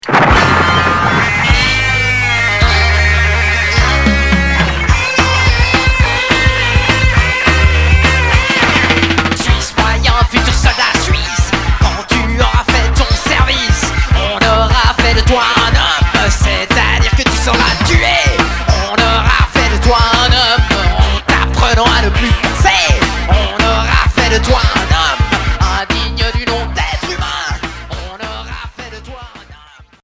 Punk décalé